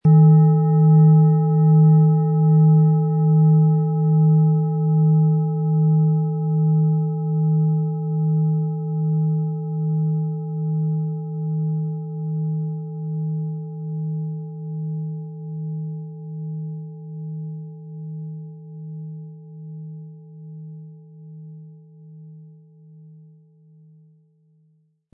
Von Hand getriebene tibetanische Planetenschale Wasserstoffgamma.
• Tiefster Ton: Mond
PlanetentöneWasserstoffgamma & Mond
MaterialBronze